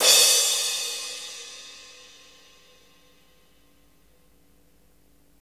CYM CRASH00L.wav